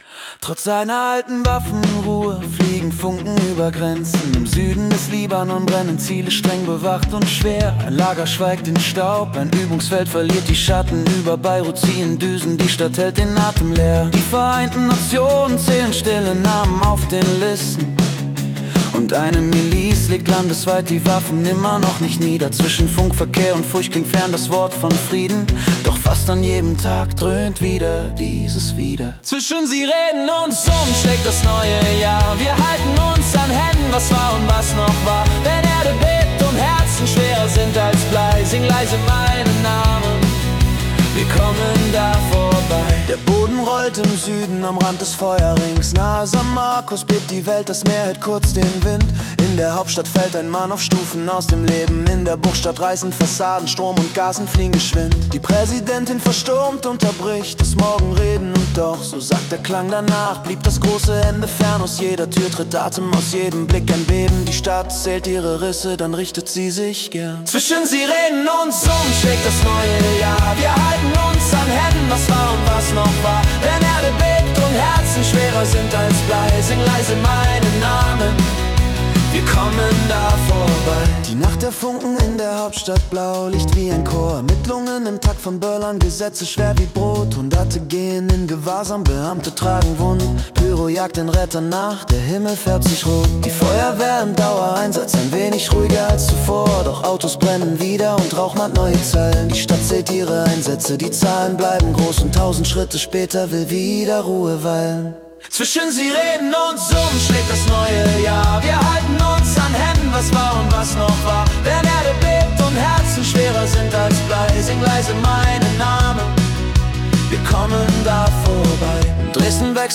Die Nachrichten vom 3. Januar 2026 als Singer-Songwriter-Song interpretiert.